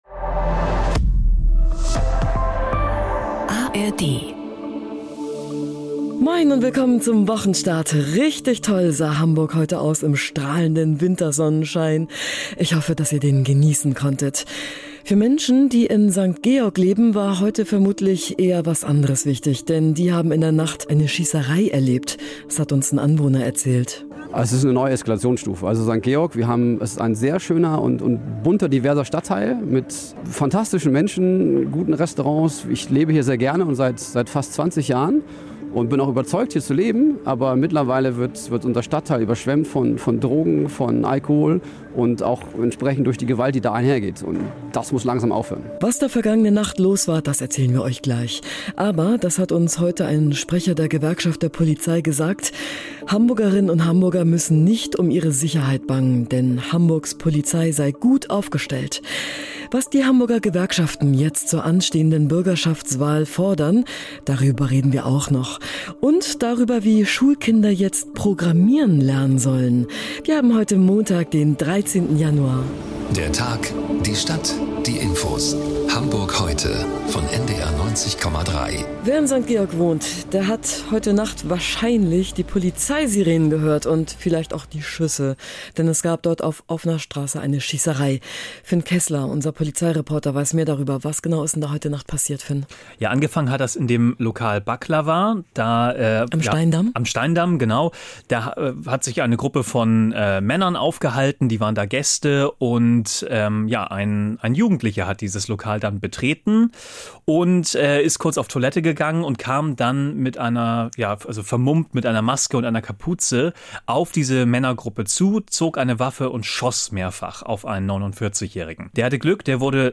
Unsere Reporterinnen und Reporter sind für Sie sowohl nördlich als auch südlich der Elbe unterwegs interviewen Menschen aus Wirtschaft, Gesellschaft, Politik, Sport und Kultur.
… continue reading 496 tập # NDR 90,3 # NDR 90 # Tägliche Nachrichten # Nachrichten # St Pauli